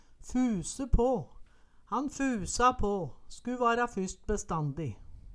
Høyr på uttala Ordklasse: Uttrykk Kategori: Uttrykk Attende til søk